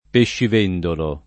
vai all'elenco alfabetico delle voci ingrandisci il carattere 100% rimpicciolisci il carattere stampa invia tramite posta elettronica codividi su Facebook pescivendolo [ peššiv % ndolo ] s. m. — pronunzia dell’ -e- tonica come in vendere — cfr. pesciaiolo ; vendere